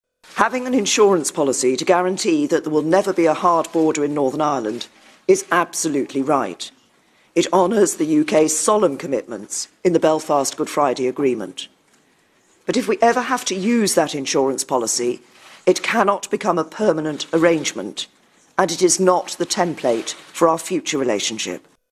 Theresa May says an agreement between the EU and UK on the backstop has now been found: